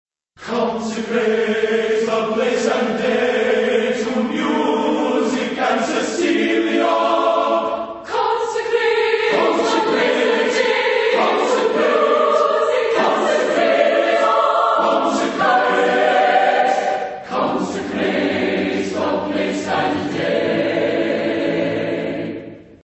Epoque: 20th century  (1960-1969)
Genre-Style-Form: Secular
Type of Choir: SATB divisi  (4 mixed voices )
Tonality: A major